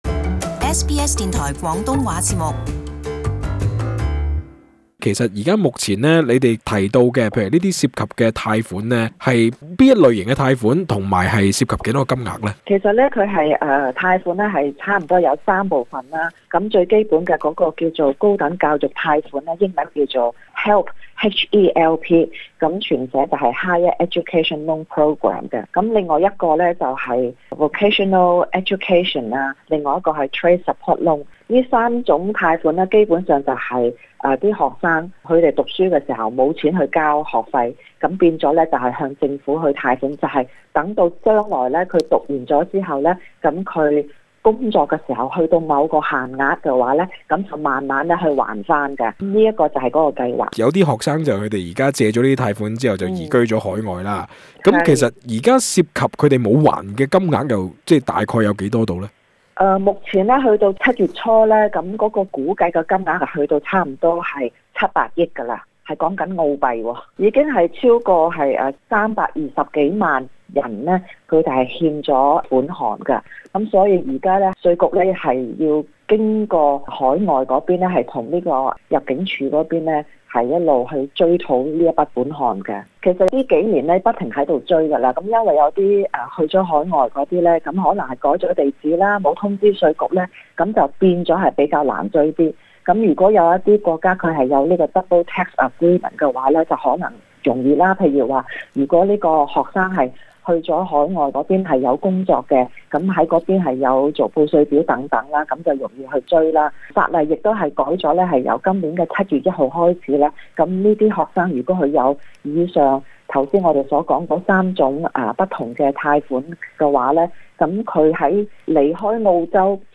【社區專訪】澳洲稅務局學生貸款新規定